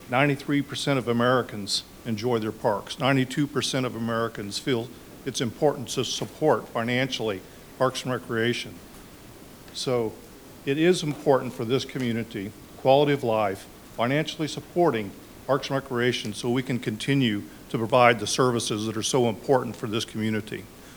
July is National Parks and Recreation Month and the Manhattan City Commission recognized the city’s department at Tuesday night’s meeting.